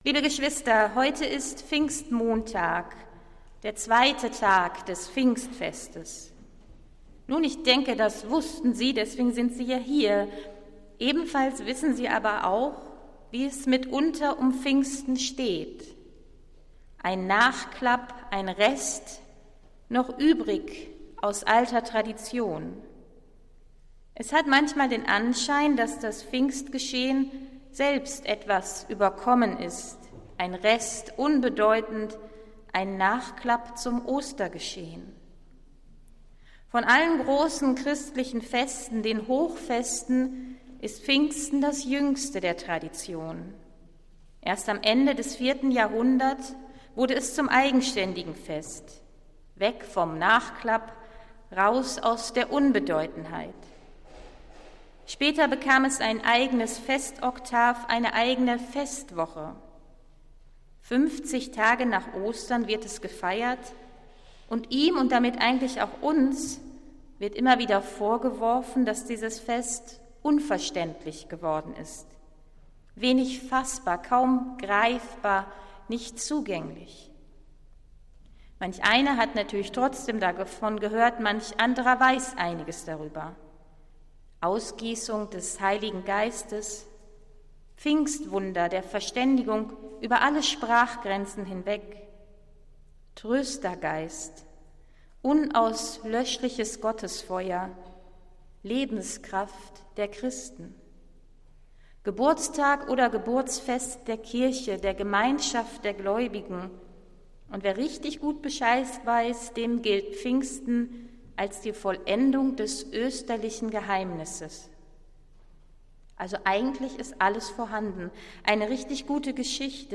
Predigt des Gottesdienstes aus der Zionskirche vom Pfingstmontag, 06.06.2022